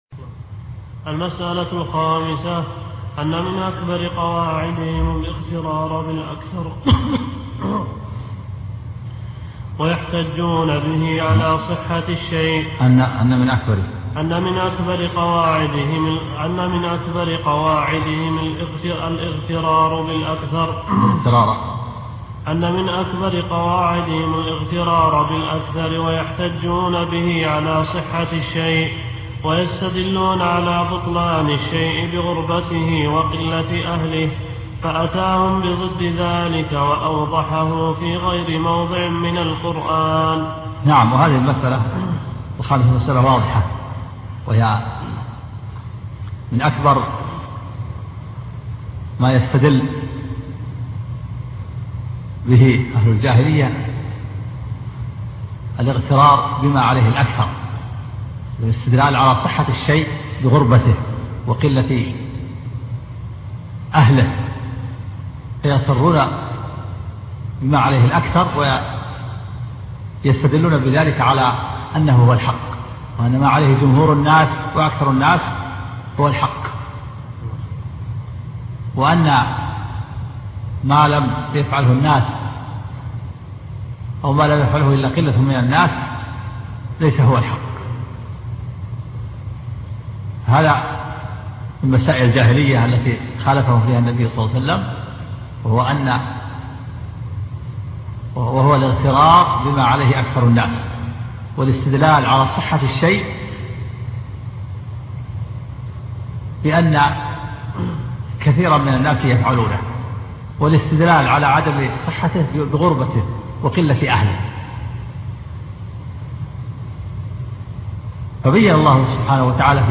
الدرس 6